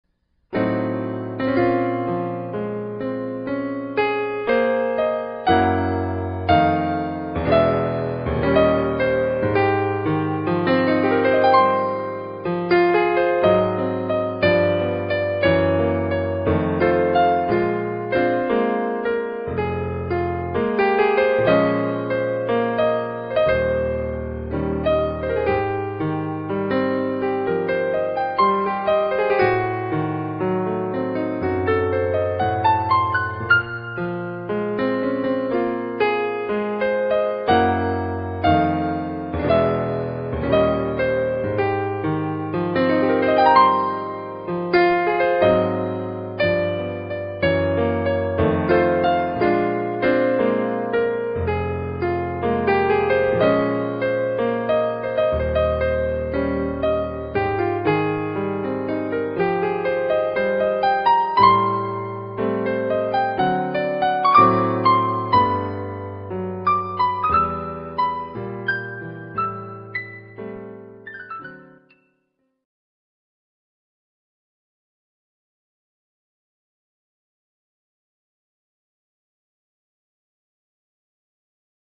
Solo Piano / Keyboard:
Ballad